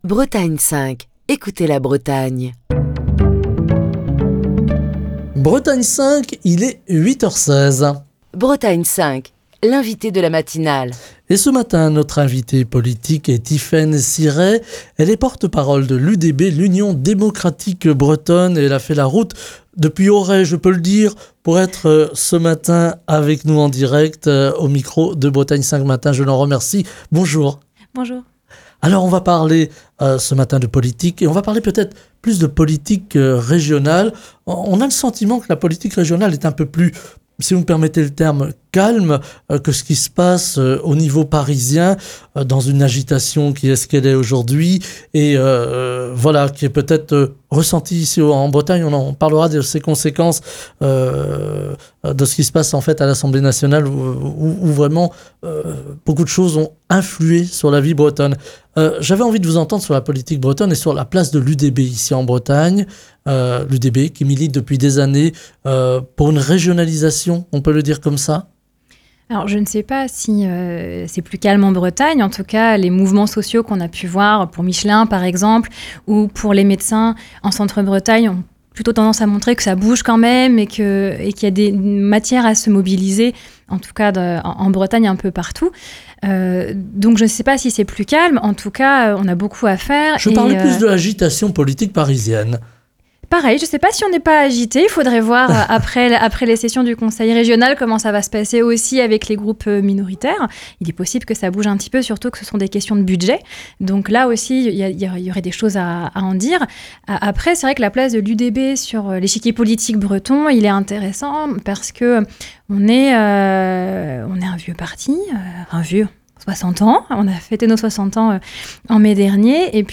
Émission du 6 décembre 2024. Mercredi soir, un vote historique de l'Assemblée nationale a entraîné la chute du gouvernement de Michel Barnier.